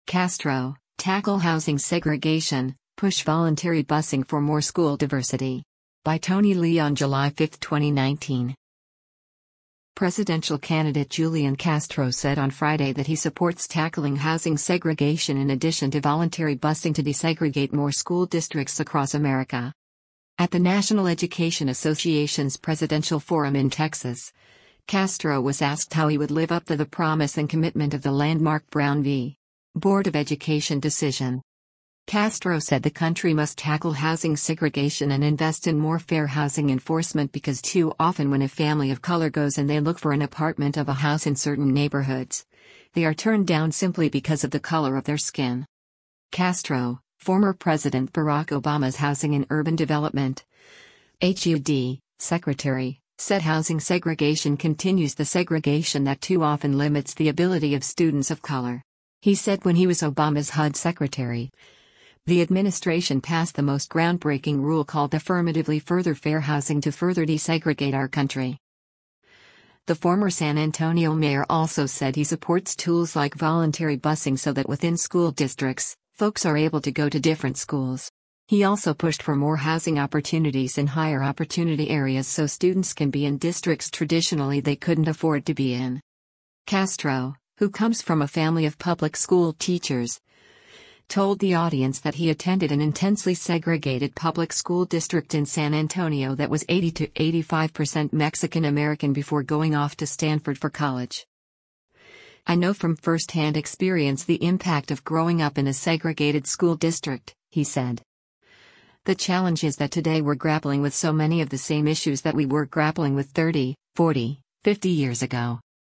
At the National Education Association’s presidential forum in Texas, Castro was asked how he would live up the the “promise and commitment” of the landmark Brown v. Board of Education decision.